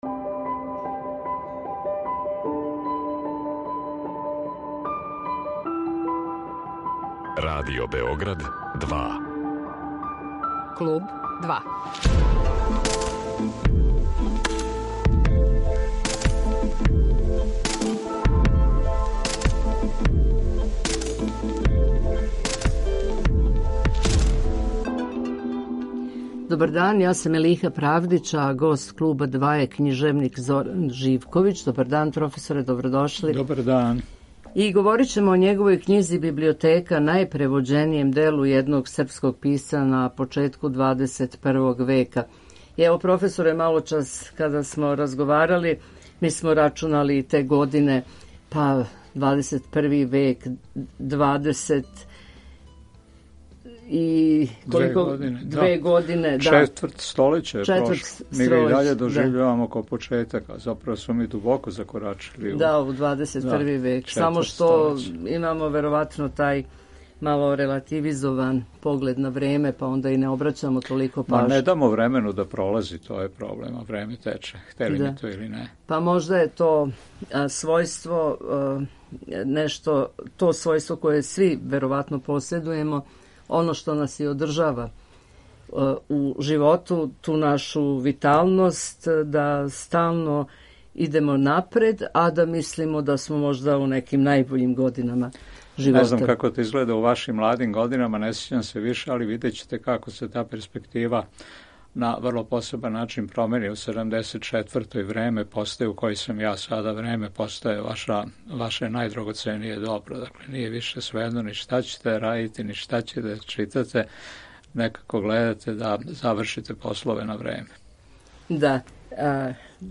Гост Клуба 2 је књижевник Зоран Живковић, а говоримо о његовој књизи „Библиотека", најпревођенијем делу једног српског писца у 21. веку